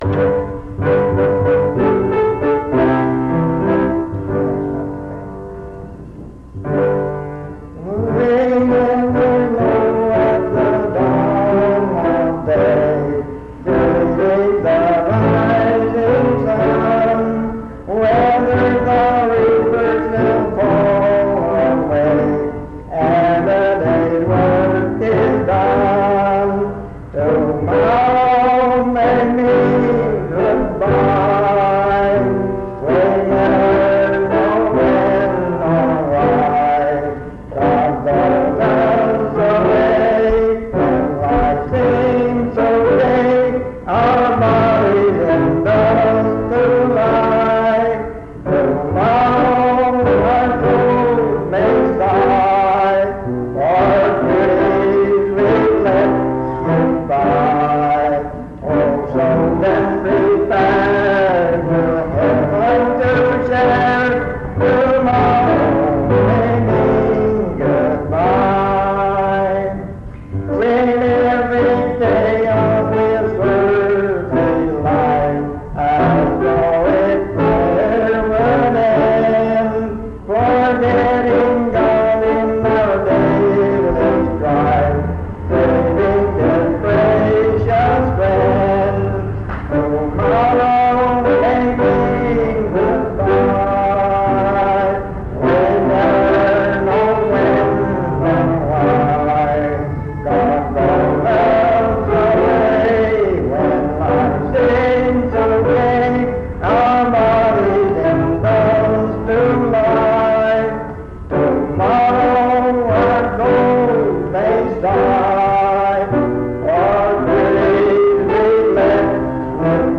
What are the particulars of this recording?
This recording is from the Monongalia Tri-District Sing. Mount Union Methodist Church II, rural, Monongalia County, WV, track 145Q.